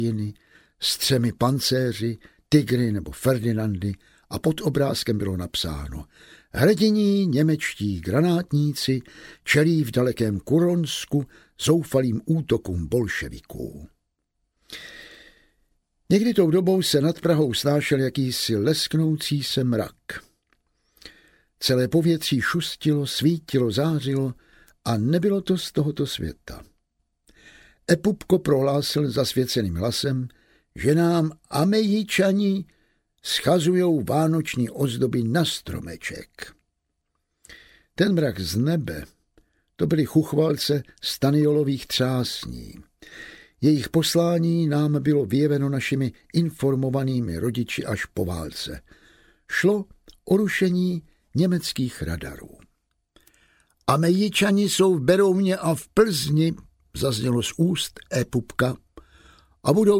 Audiobook
Read: Jan Vlasák